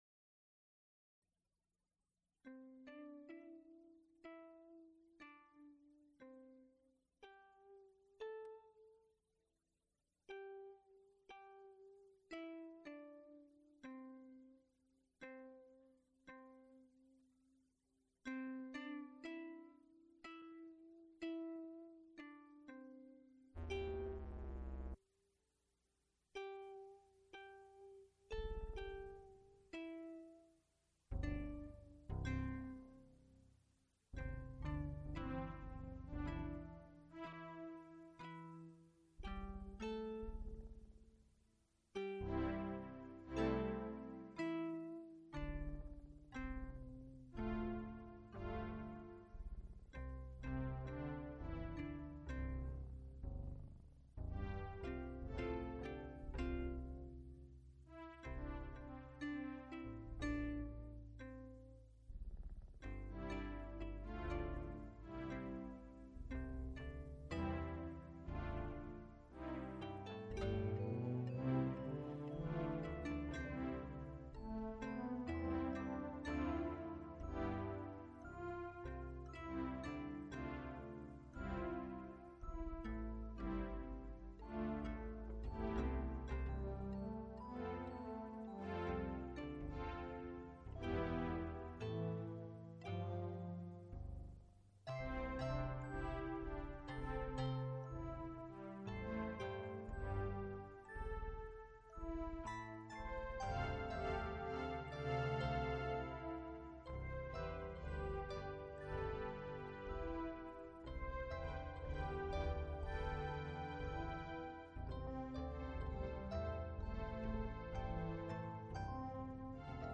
A Concert of electronic music | Digital Pitt
All compositions realized in the Computer-Electronic Music Studio of the University of Pittsburgh.
Recorded December 8, 1987, Frick Fine Arts Auditorium, University of Pittsburgh.
Extent 2 audiotape reels : analog, half track, 7 1/2 ips ; 12 in.
Electronic music